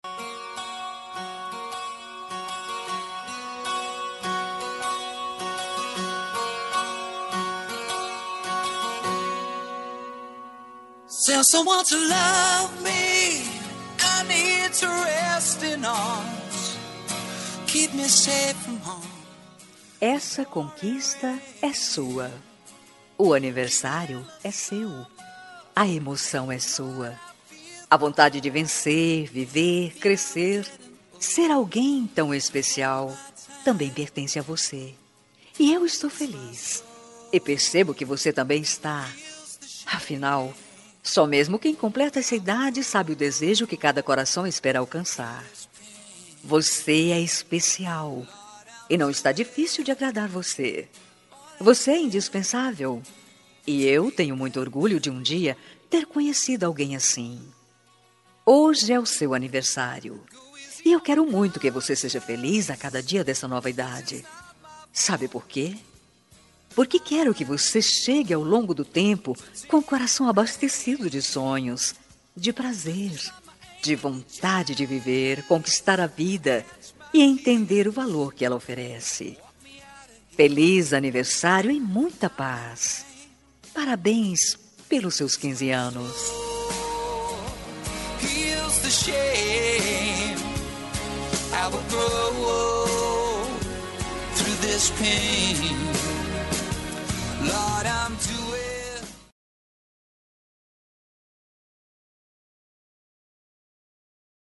Aniversário de 15 anos – Voz Feminina – Cód: 33373